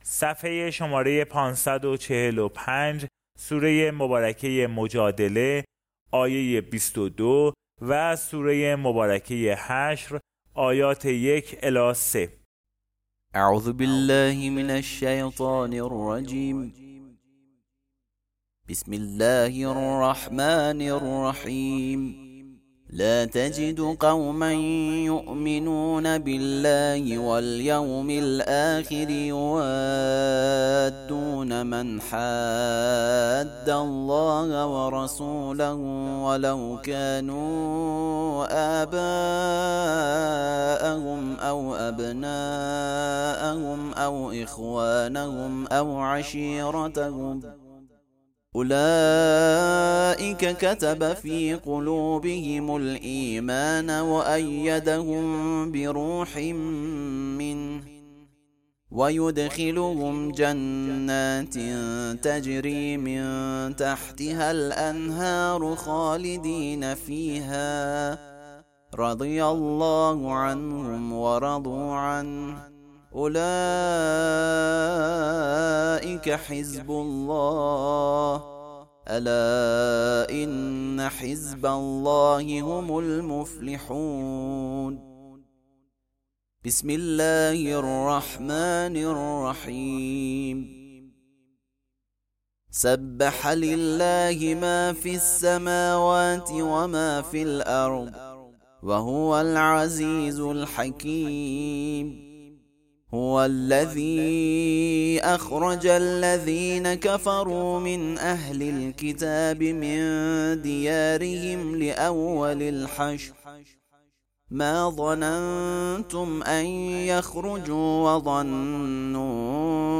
ترتیل صفحه ۵۴۵ از سوره مجادله و حشر (جزء بیست و هشتم)
ترتیل سوره(مجادله، حشر)